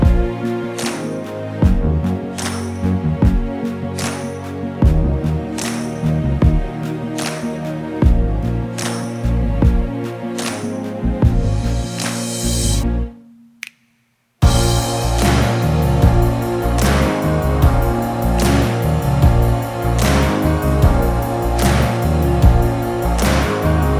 Pop (2020s)